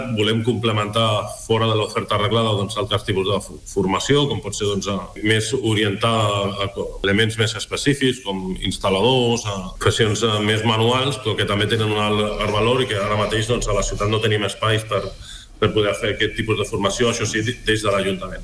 L’alcalde de Mataró, David Bote, ha passat per l’Entrevista del Dia de RCT per parlar del nou projecte d’ampliació del TecnoCampus.